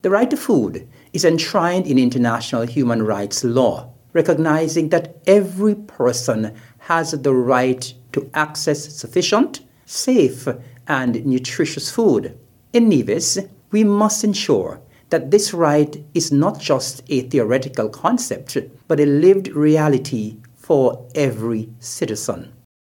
In light of the 2024 theme: “Right to foods for a better life and a better future”, Deputy Premier of Nevis and Minister of Agriculture, the Hon. Eric Evelyn, mentioned: